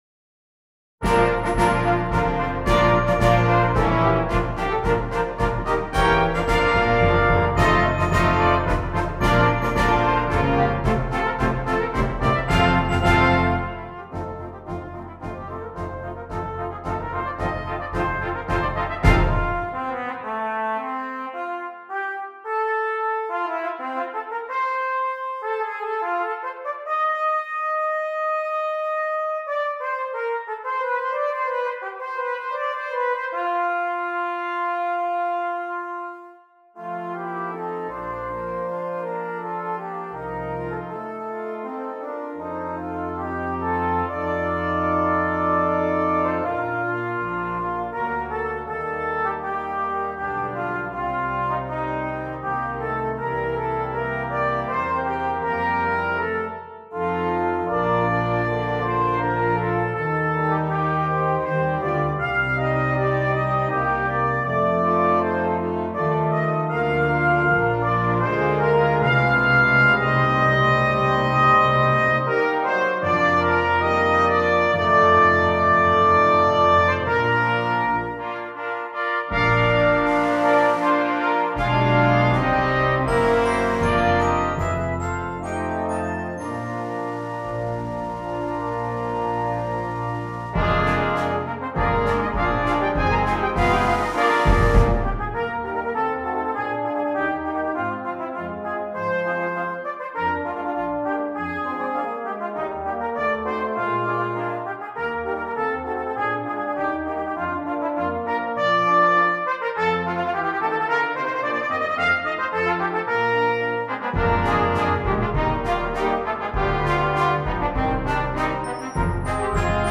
Voicing: 14 Brass w/ Percussion